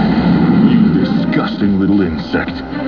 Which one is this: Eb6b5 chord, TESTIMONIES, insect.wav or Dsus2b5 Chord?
insect.wav